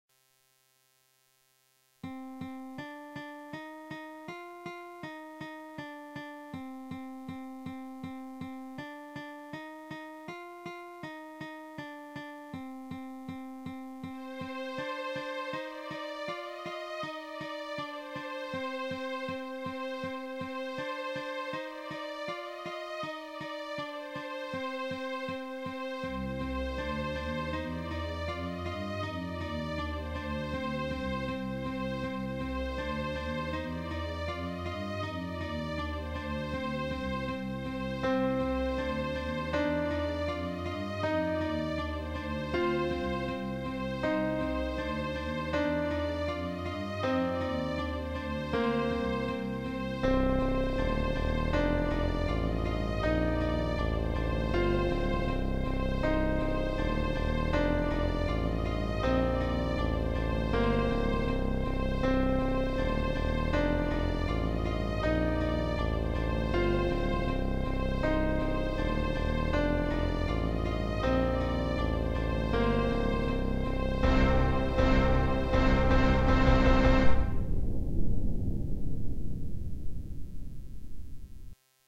Maybe something for Warcraft or an old RPG game...